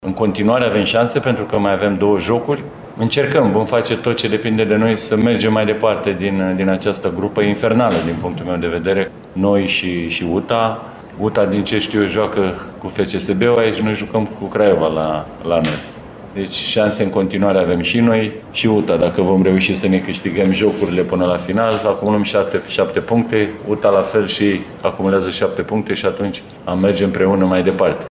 Legat de perspectivă, Mihalcea părea deja cu gândul la jocul important de campionat cu Metaloglobus, în vreme ce Neagoe făcea calcule pentru ieșirea din grupele de Cupa României: